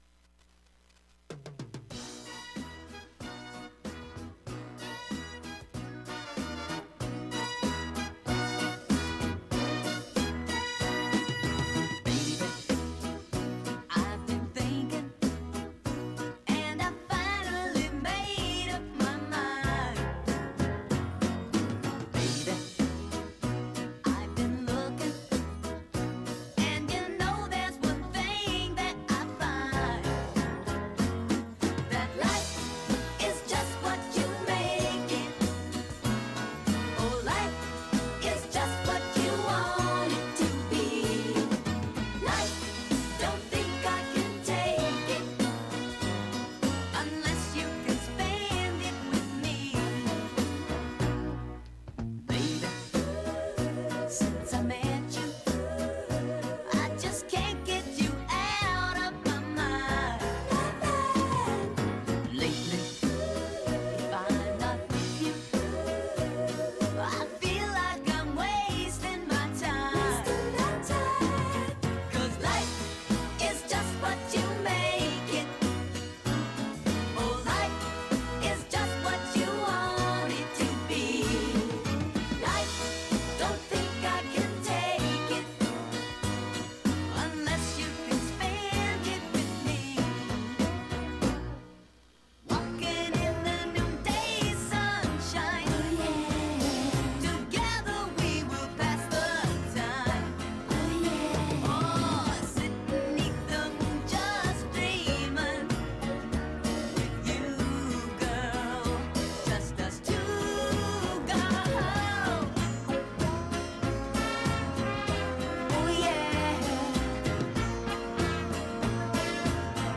Huzzah! Tonight we combined our usual late ’60s and early ’70s special into one broadcast with lots of fun tunes to put a spring in your step this (slowly) warming week!